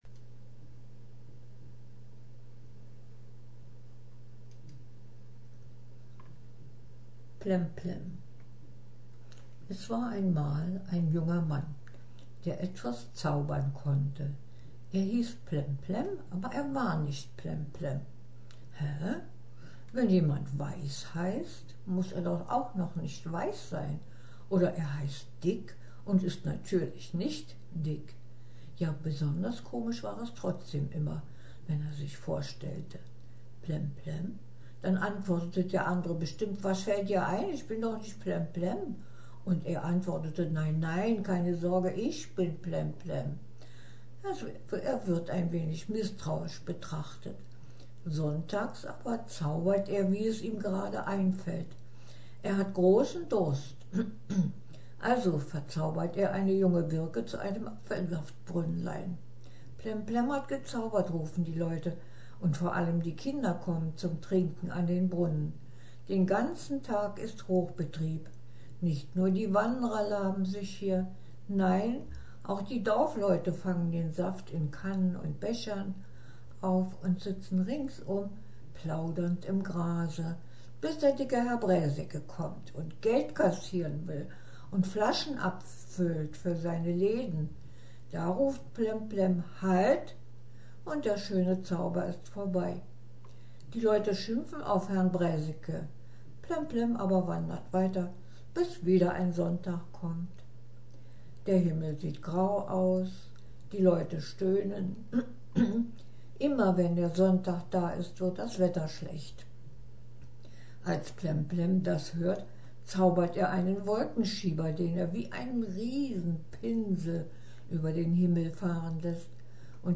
Gesprochener Text